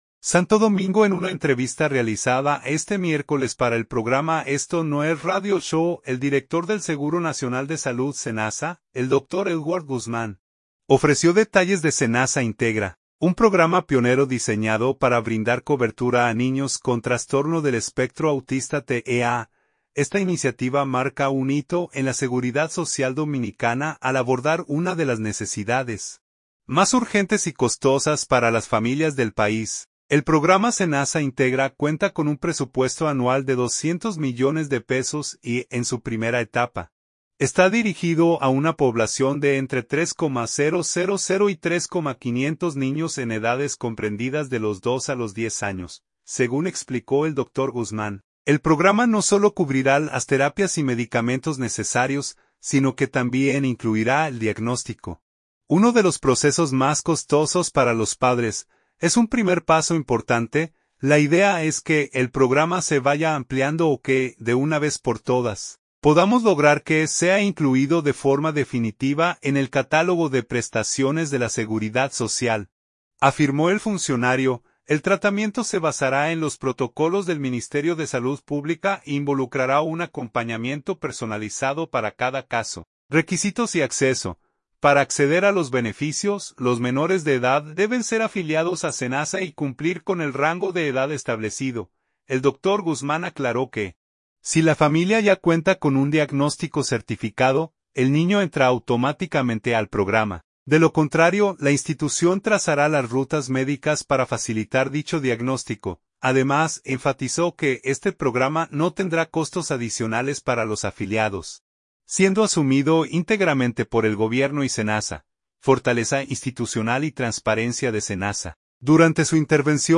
SANTO DOMINGO – En una entrevista realizada este miércoles para el programa "Esto No es Radio Show", el director del Seguro Nacional de Salud (SENASA), el doctor Edward Guzmán, ofreció detalles de "SENASA Integra", un programa pionero diseñado para brindar cobertura a niños con trastorno del espectro autista (TEA).